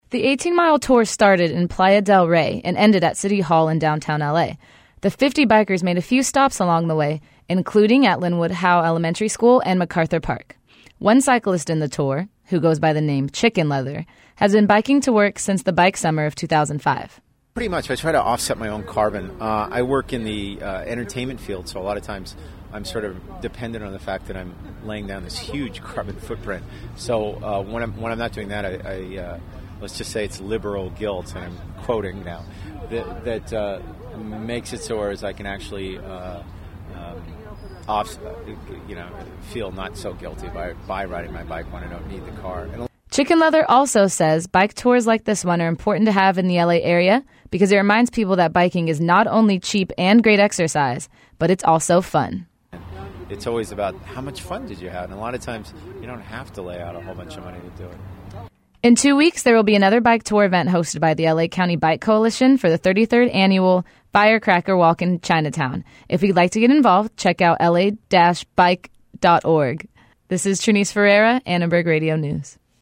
audio story